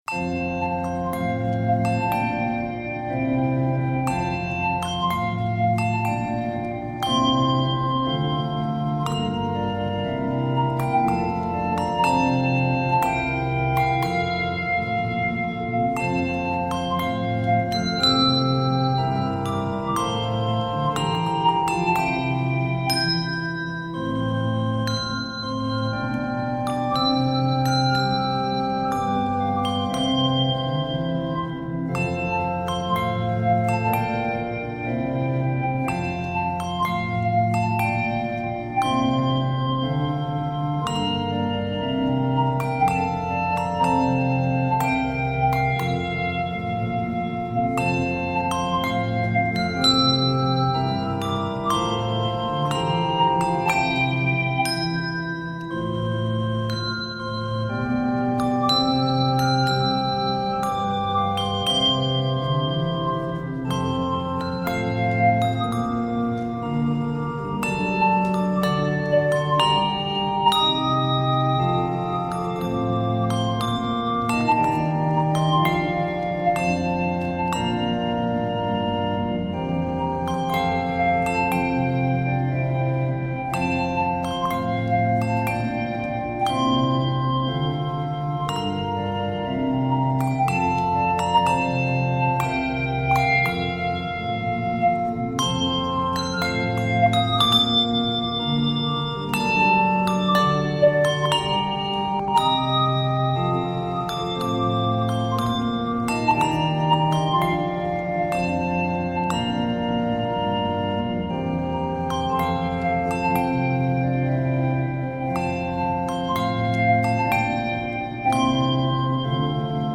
The organ accompaniments